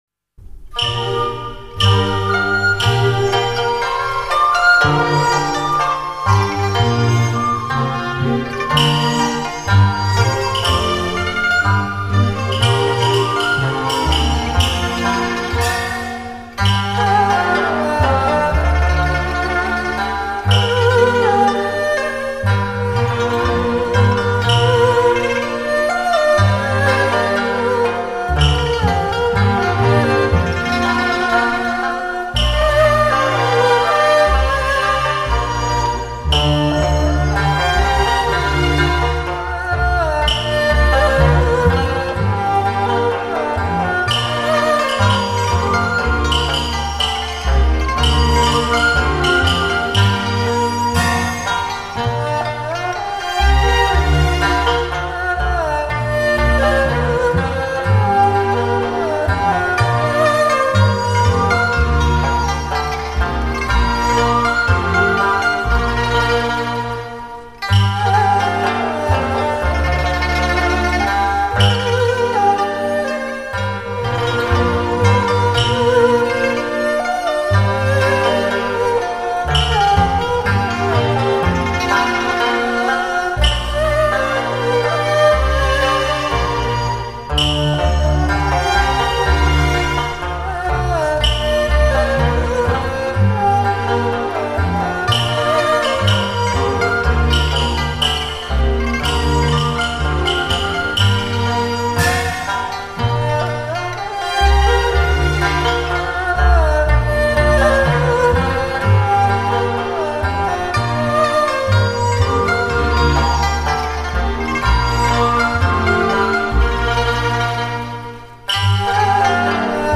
录音地点:北京农影制片厂录音棚
大气的录音  就是很喜欢的调调  O(∩_∩)O谢谢